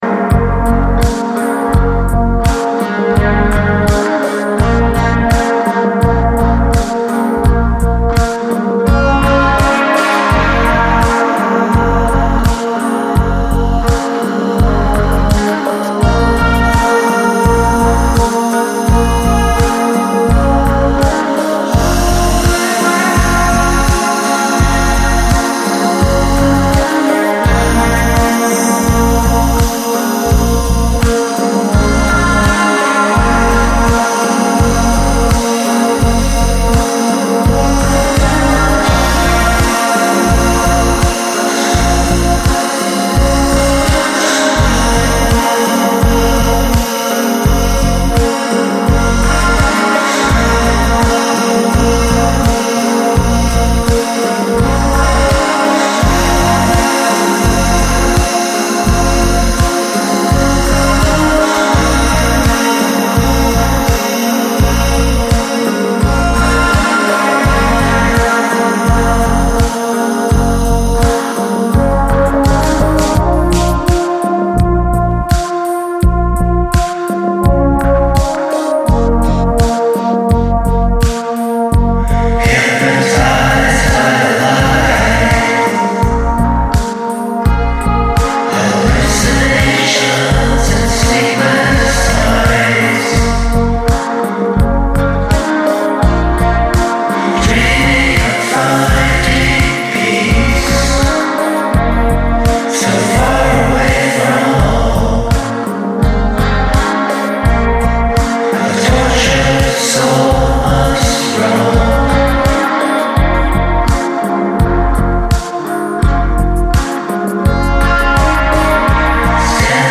幻想的で気怠いシンセ・ポップをダウンテンポで展開していったB-1